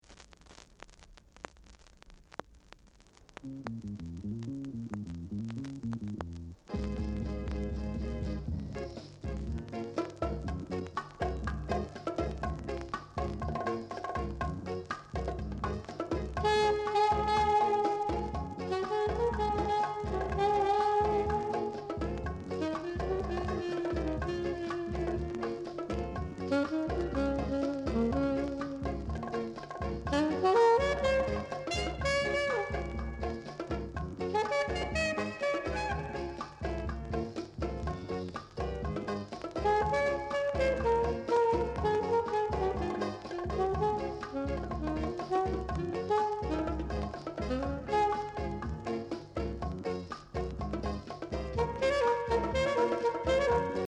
Calypso Inst
Nice calypso inst!